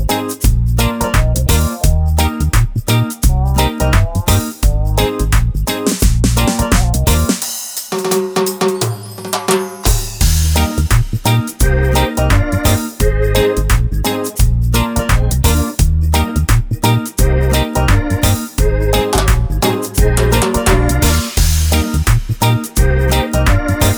With Rapper Pop (1990s) 3:30 Buy £1.50